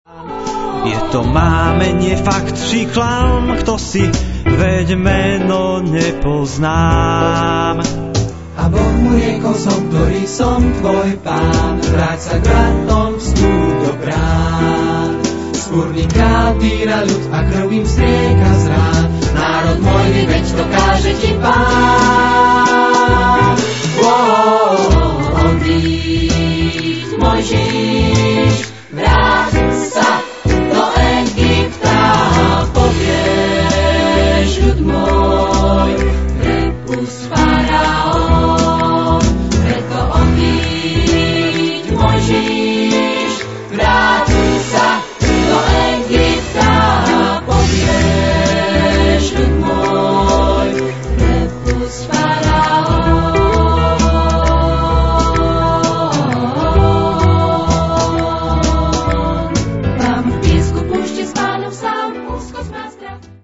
pôvodný slovenský muzikál